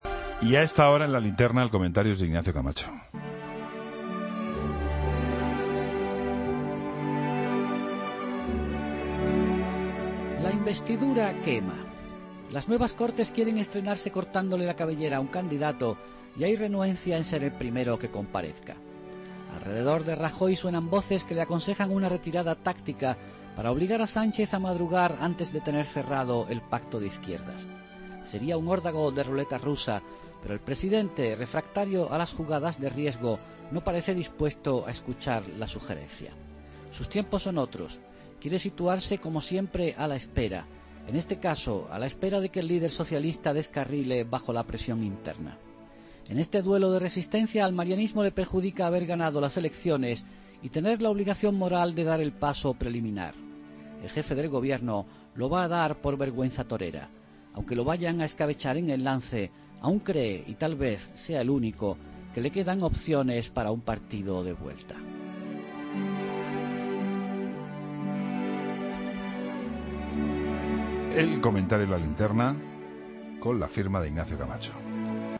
AUDIO: Comentario de Ignacio Camacho en La Linterna